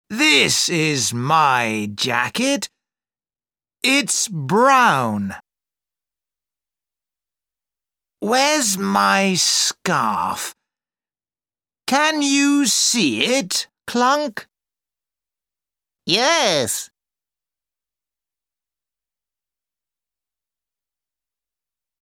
Track 2 Where's My Hat British English.mp3